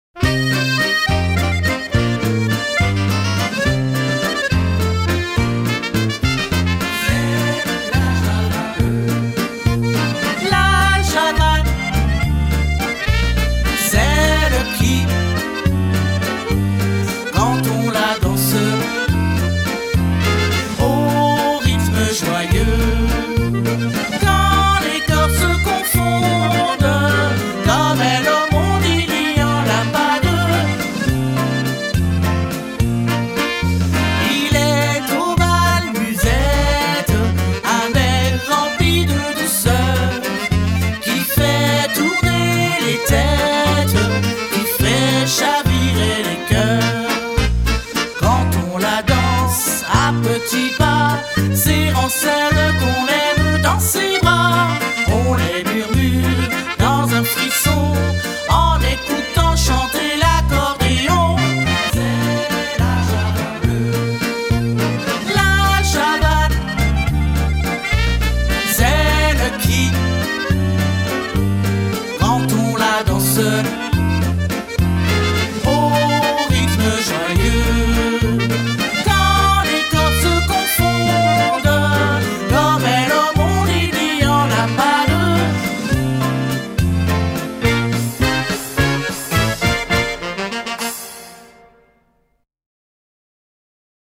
La version chantée avec les trous (Facile)